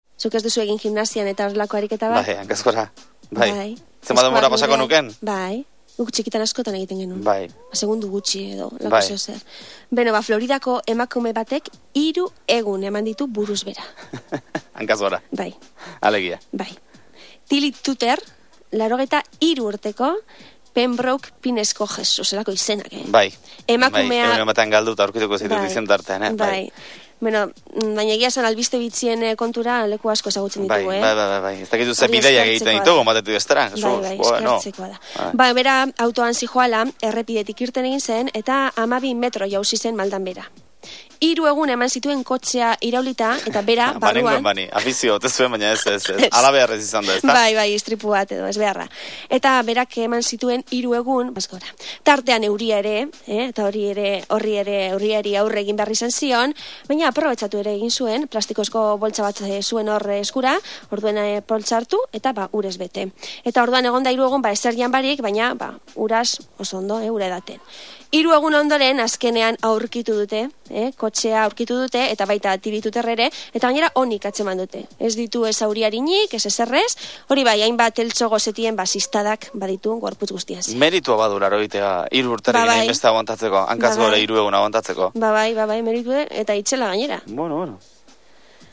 Entzungaian bi pertsona (neska eta mutila) ari dira berriaren inguruan. Neskak emango du eta zenbaitetan bai neskak bai mutilak komentarioak egingo dituzte ematen ari den berriaren inguruan.
En el texto se escucha a dos personas (chico y chica).